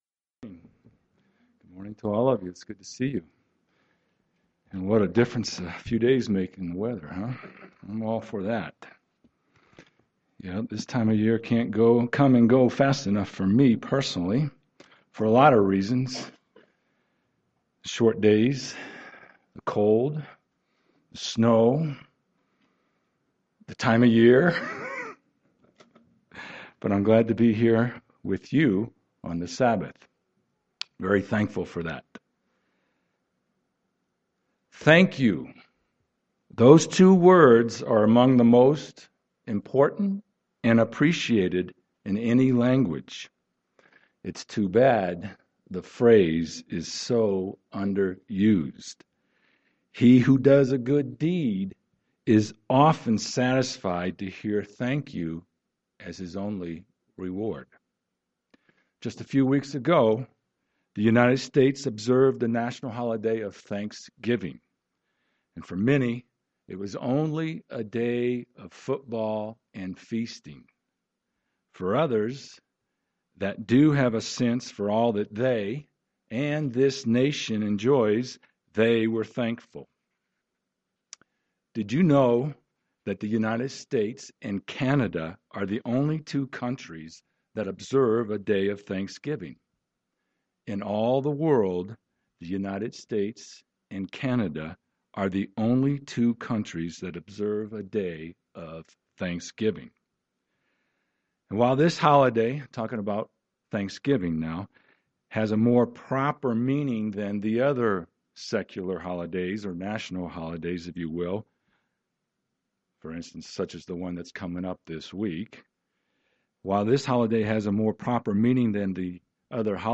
How can we be more grateful even in the face of difficult circumstances? This sermon explores giving thanks from a Biblical perspective.